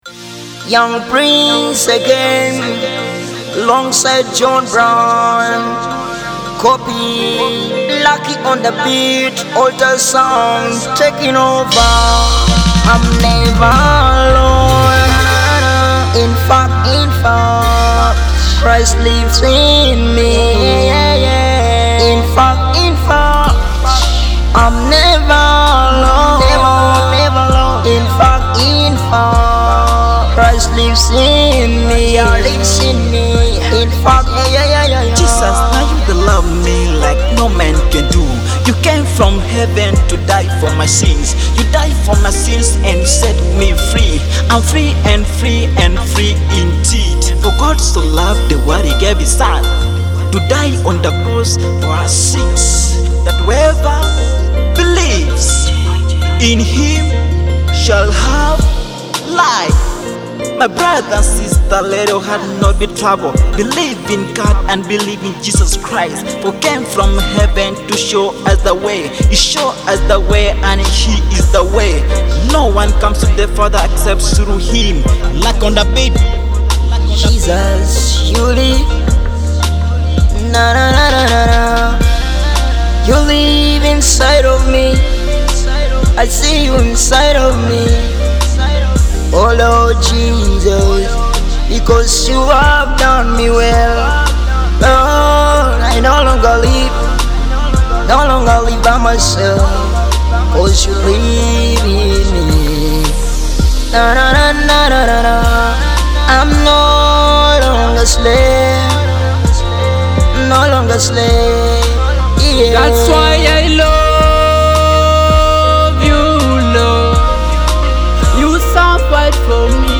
an inspiring Teso gospel song.